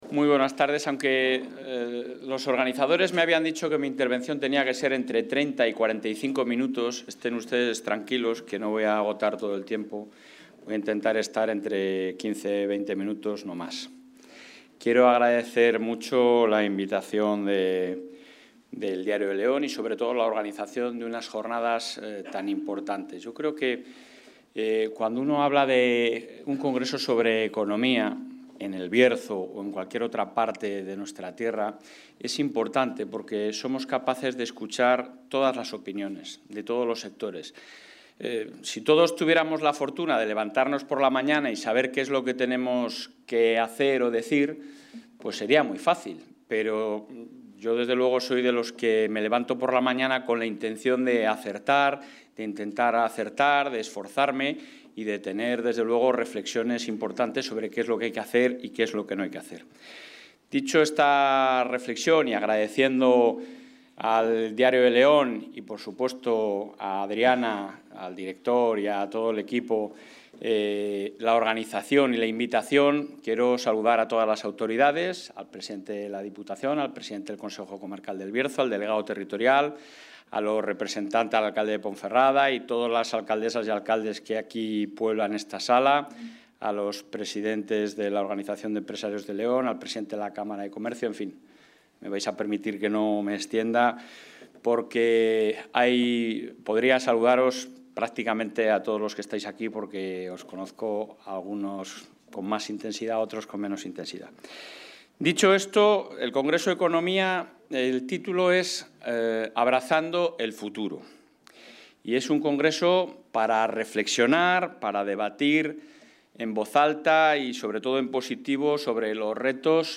Durante la clausura de la primera jornada del I Congreso sobre la Economía de El Bierzo: “Abrazando el futuro”, organizado por el...
Intervención presidente.